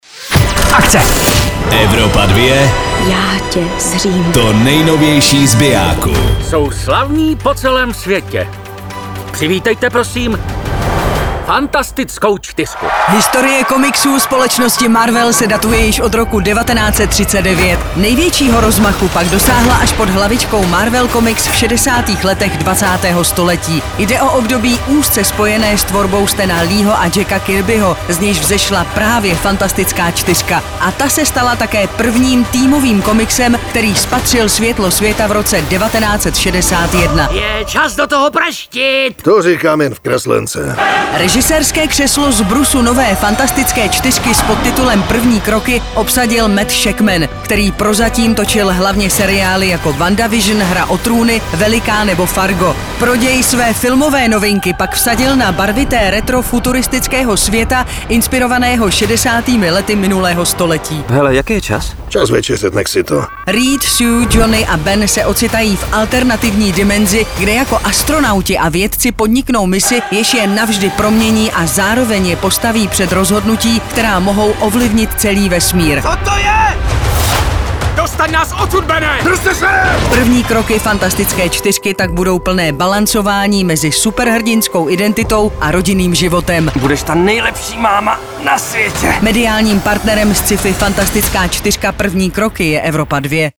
filmový trailer